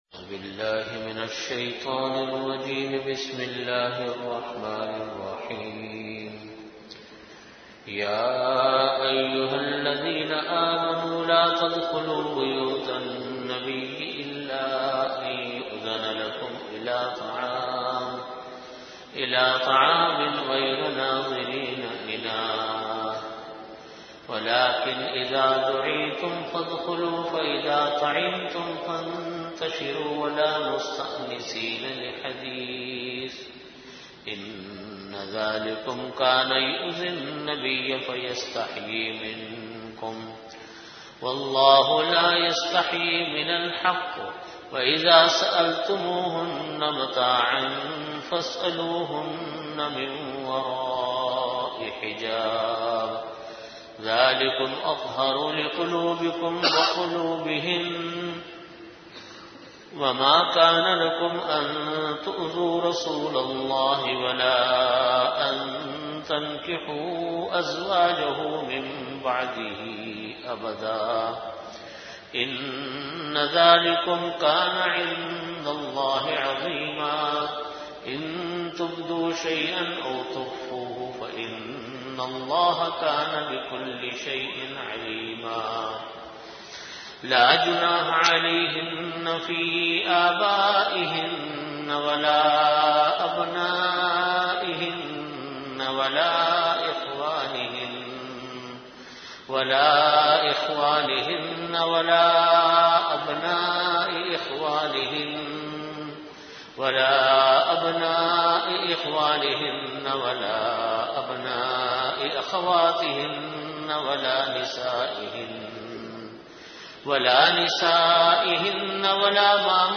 Tafseer Surah Al-Ahzaab - XIX (Dawat Aur Parday Ke Ahkaam)
Time: After Asar Prayer Venue: Jamia Masjid Bait-ul-Mukkaram, Karachi